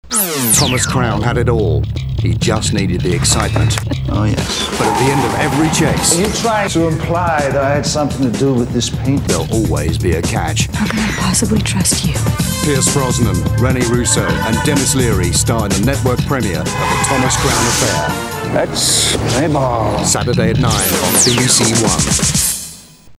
Voiceovers
Thomas Crown Affair (BBC TV Trailer)